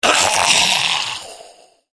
troll_mage_die.wav